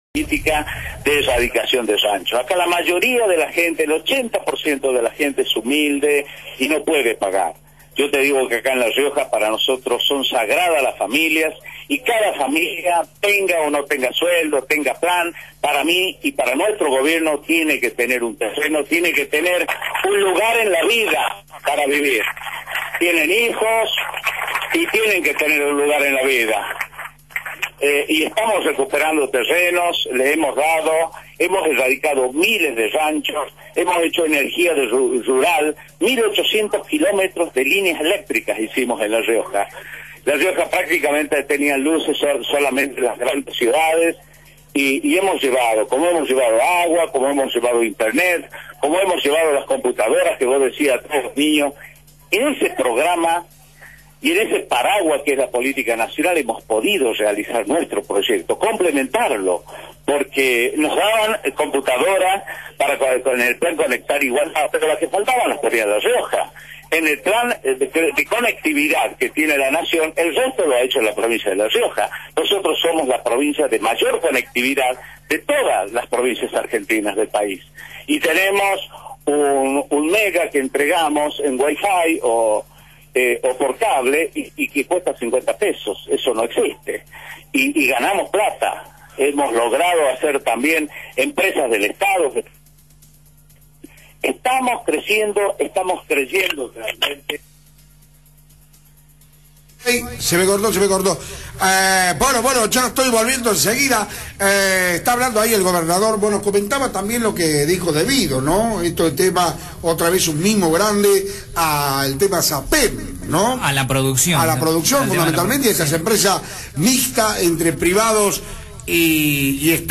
Discurso del vicepresidente de la Nación  Luis Beder Herrera, gobernador, por Radio Fénix  Amado Boudou, vicepresidente, por Radio Fénix
luis-beder-herrera-gobernador-por-radio-fc3a9nix.mp3